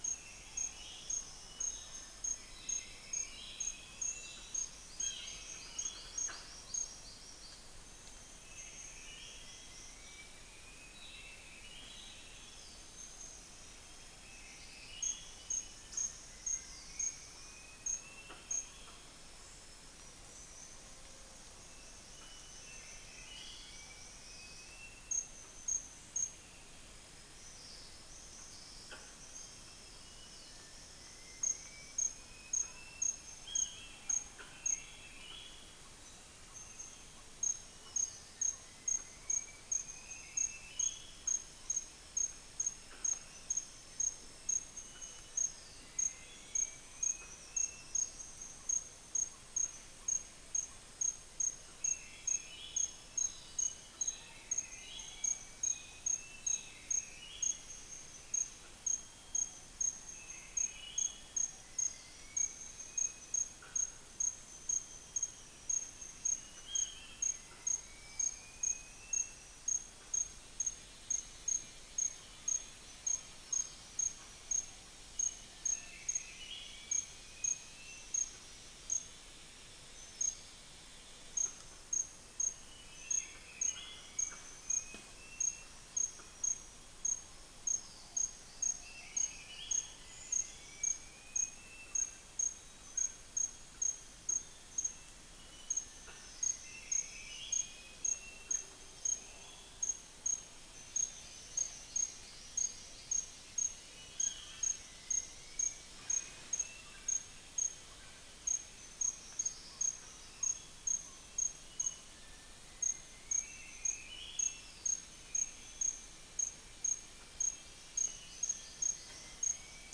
Stachyris maculata
Mixornis gularis
Malacopteron affine
Eurystomus orientalis
Dicrurus paradiseus